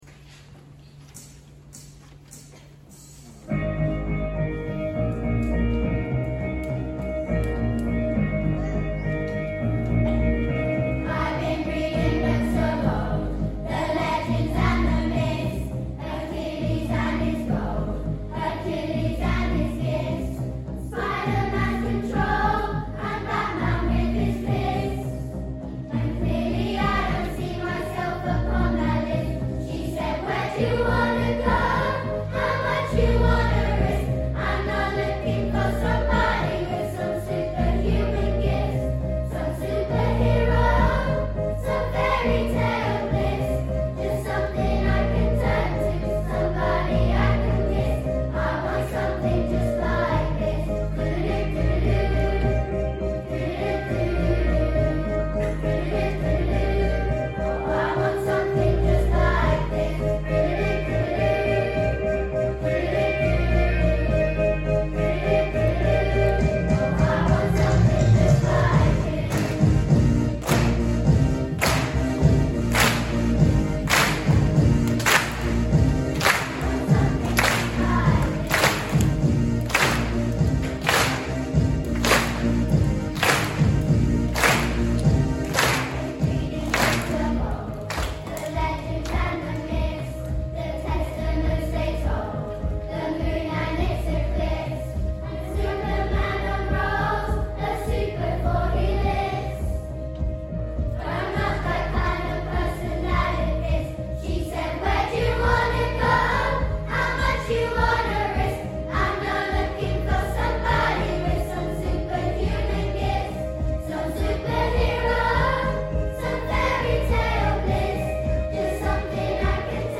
Something Just Like This | Year 4/5/6 Choir